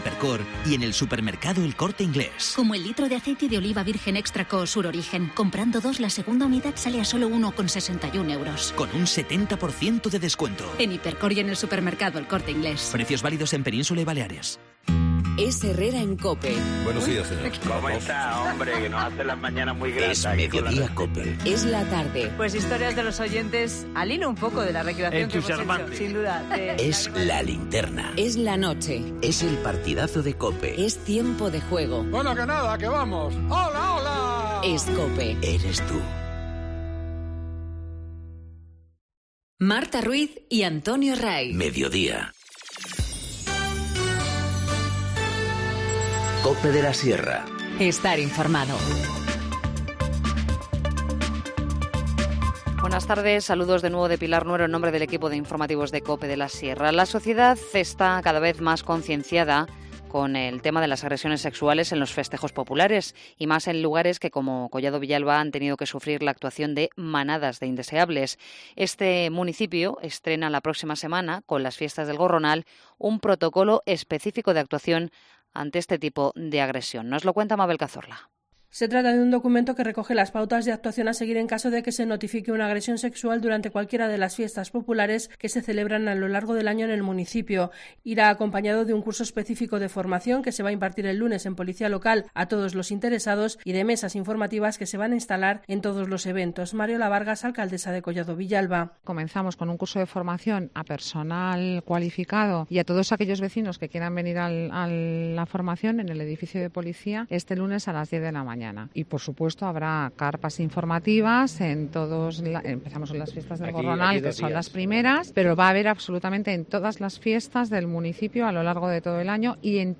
Informativo Mediodía 26 abril 14:50h